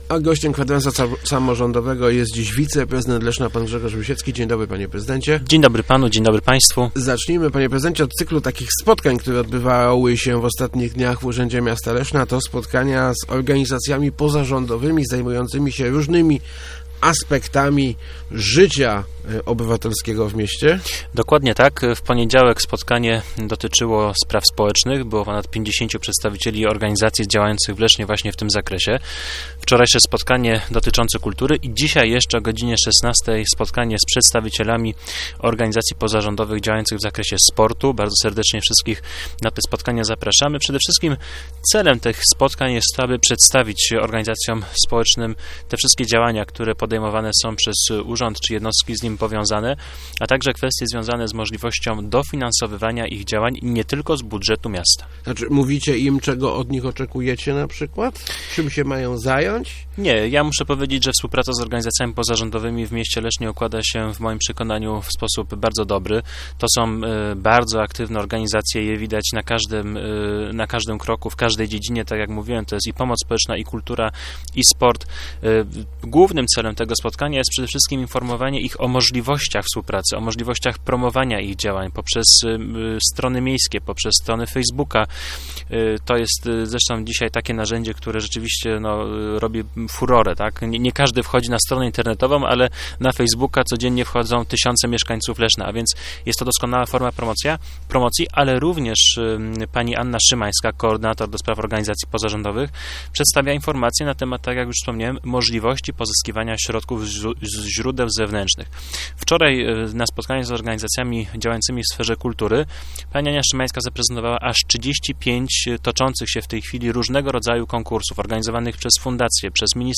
Gościem Kwadransa był wiceprezydent Grzegorz Rusiecki.